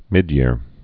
(mĭdyîr)